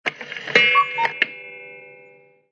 Dzwonki Zegar Kukułką
Kategorie Alarmowe